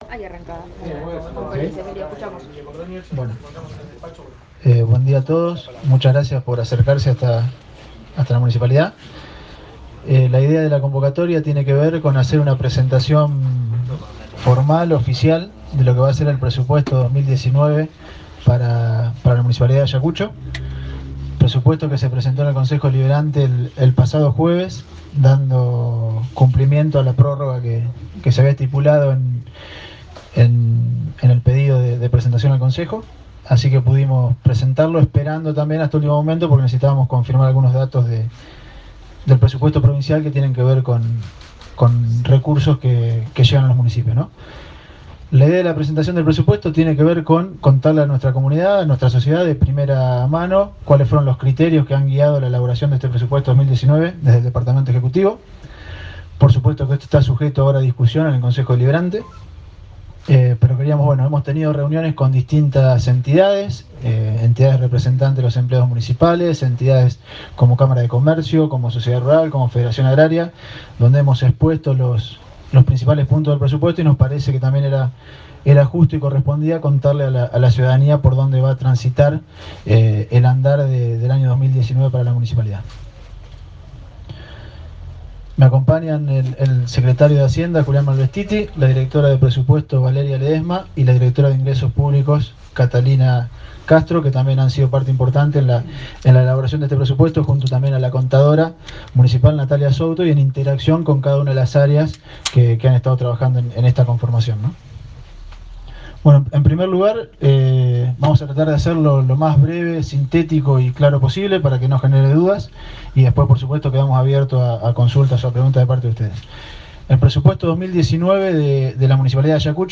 En la mañana de este Jueves, el Intendente Emilio Cordonnier acompañado por parte de su equipo de Hacienda, brindó una extensa conferencia de prensa donde explicó y desmenuzó el Presupuesto Municipal que hace días fue enviado al Concejo Deliberante para su tratamiento, y que será de alrededor de 851 millones de pesos, tanto para la Administración como para el Ente Descentralizado Hospital y Hogar de Ancianos.
1ª-parte-conferencia-de-prensa-presupuesto-2019.ogg